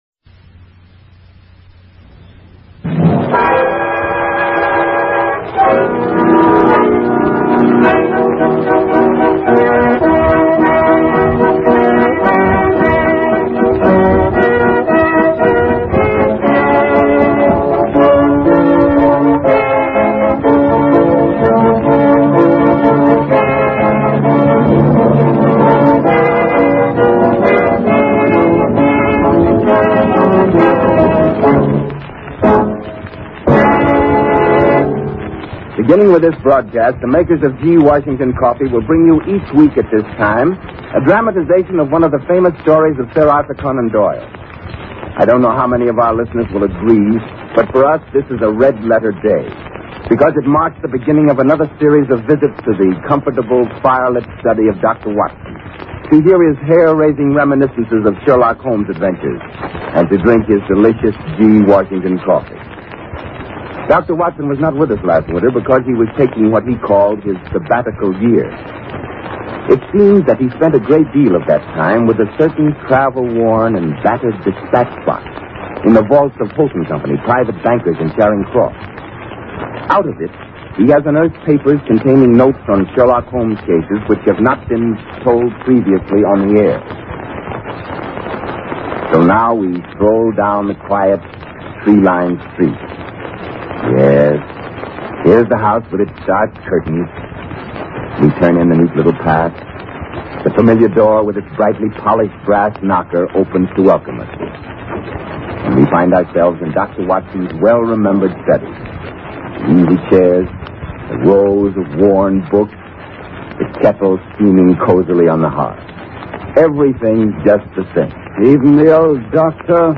Radio Show Drama with Sherlock Holmes - The Hebraic Breastplate 1934